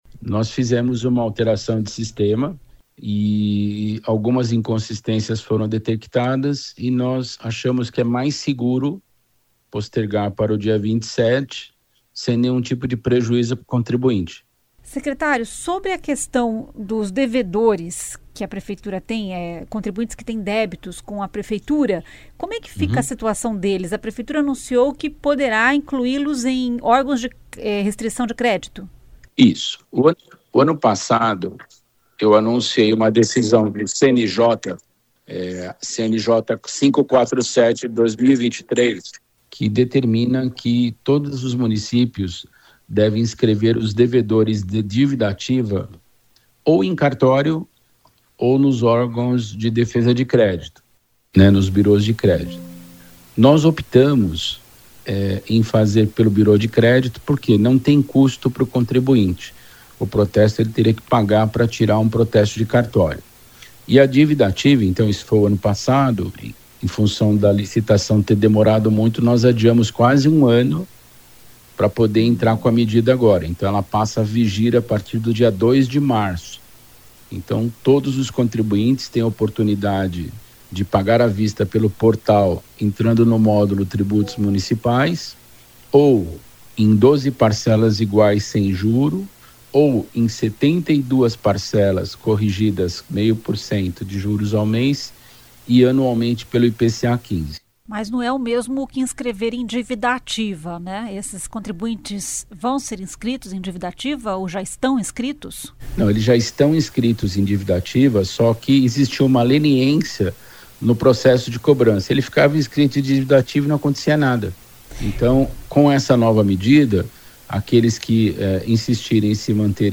O secretário Carlos Augusto Ferreira explica por que essa medida e informa também sobre a prorrogação do prazo para pagamento de ISS, exercício de 2026.